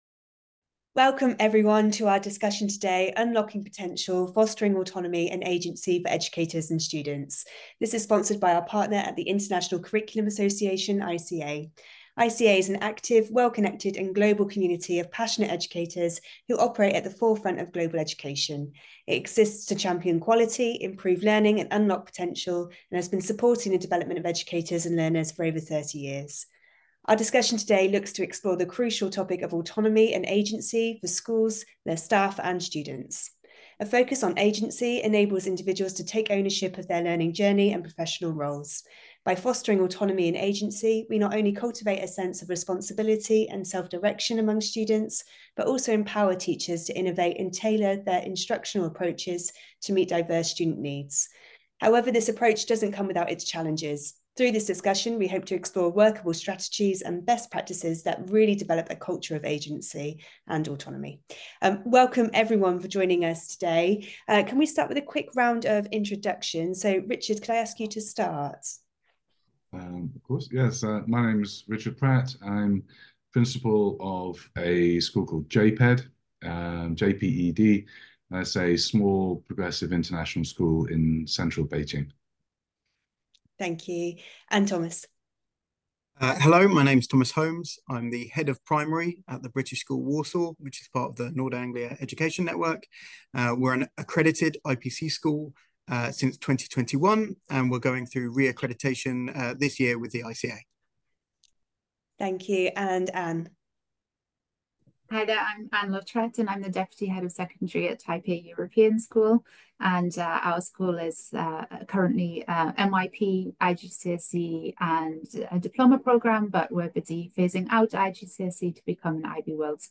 In this discussion, our experts explore workable strategies and best practices that really develop a culture of agency and autonomy.